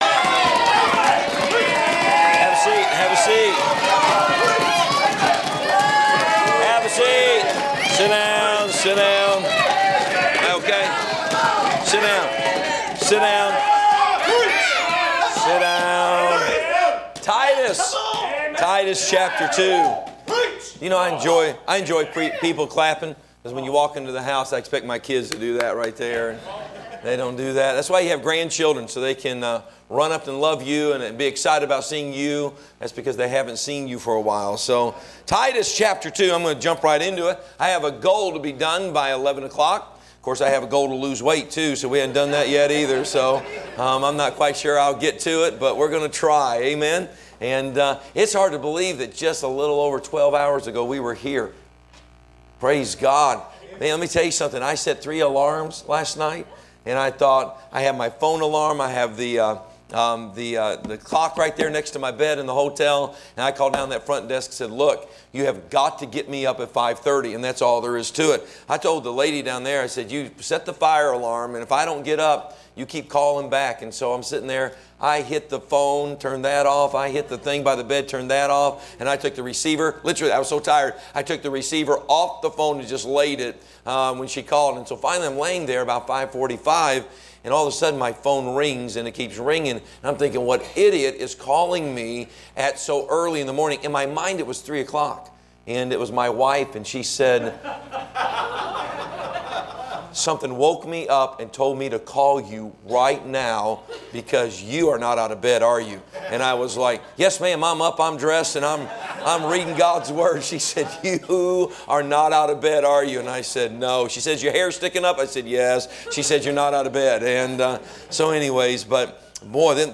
Sermons preached from the pulpit of Anchor Baptist Church in Columbus, Ohio.